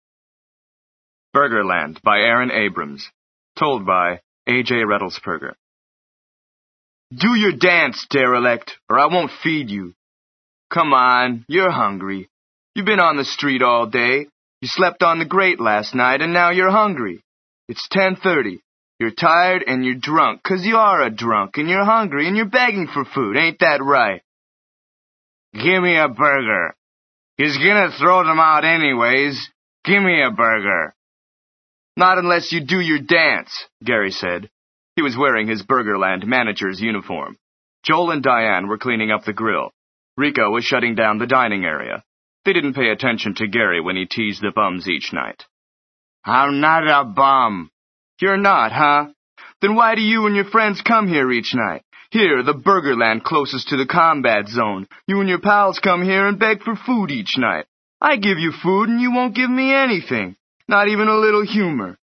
Фактически Вы платите чуть более 1 рубля за час аудиокниги, начитанной носителем языка!